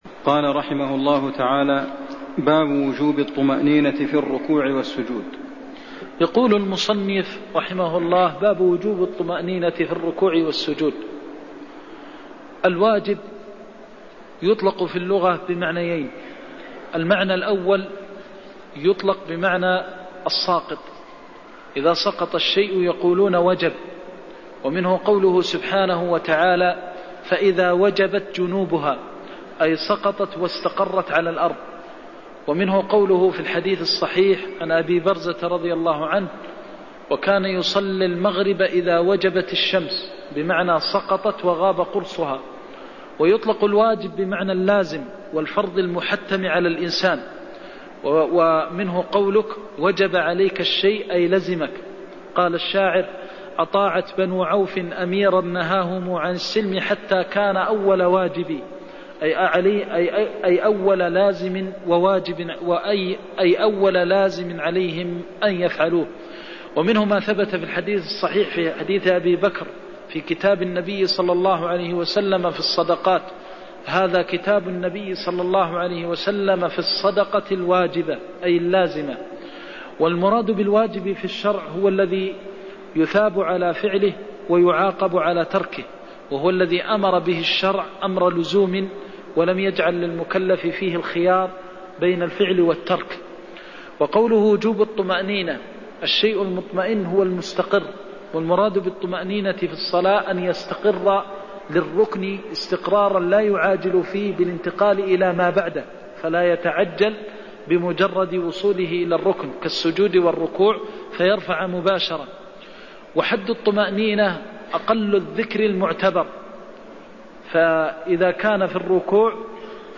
المكان: المسجد النبوي الشيخ: فضيلة الشيخ د. محمد بن محمد المختار فضيلة الشيخ د. محمد بن محمد المختار حديث المسيء صلاته (92)